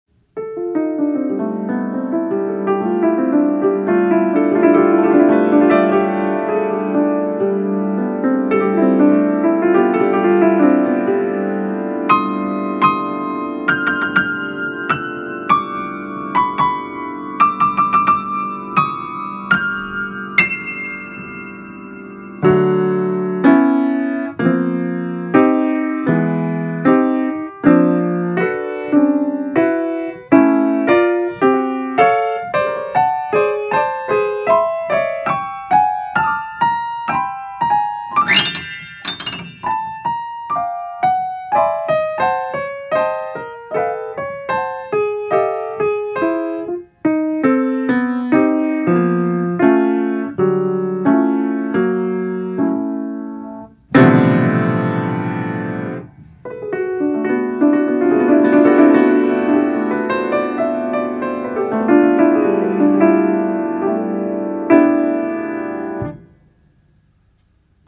Improvisatie treinreis